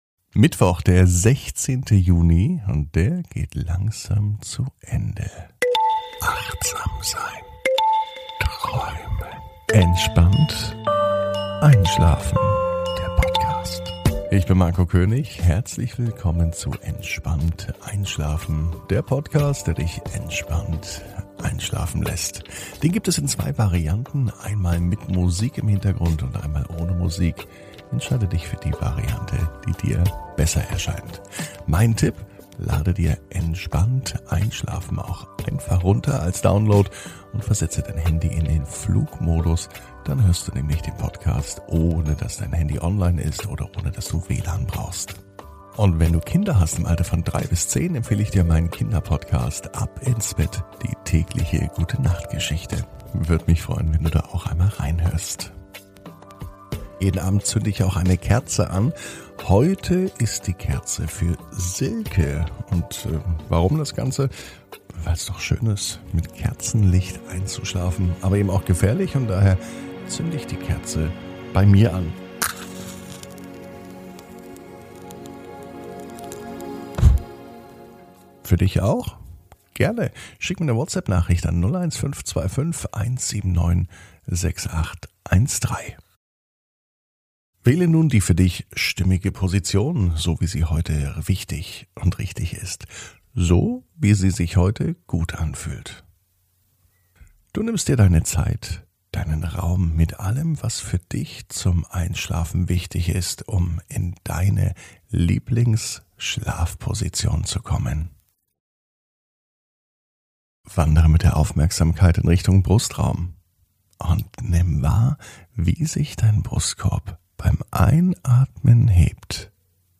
(ohne Musik) Entspannt einschlafen am Mittwoch, 16.06.21 ~ Entspannt einschlafen - Meditation & Achtsamkeit für die Nacht Podcast